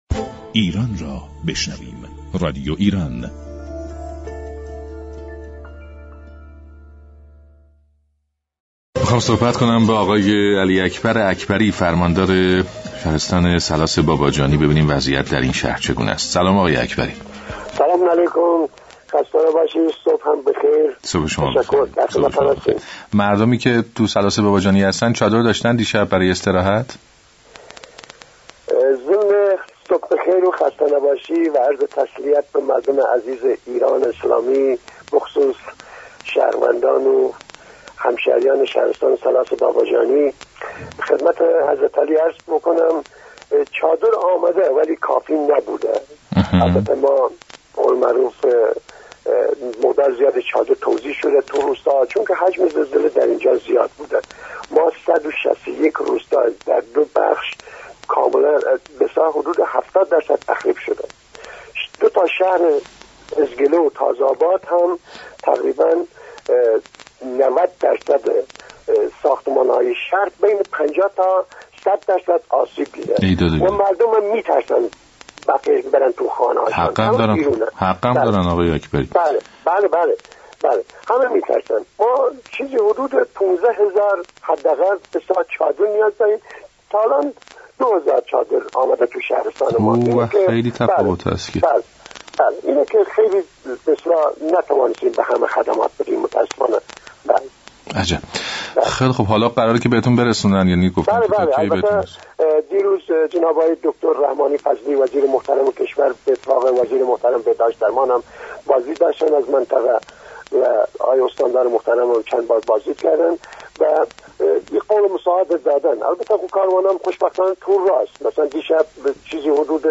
به گزارش شبكه رادیویی ایران، «علی اكبر اكبری» فرماندار شهرستان ثلاث باباجانی، در گفت و گو با برنامه ی «سلام ایران» رادیو ایران به آخرین وضعیت این شهرستان پرداخت و در این باره گفت: از آنجا كه این منطقه حجم وسیعی از زلزله را تجربه كرده و حدود 70 درصد روستاها مورد تخریب قرار گفته؛ به شدت و فراوان نیازمند كمك رسانی است.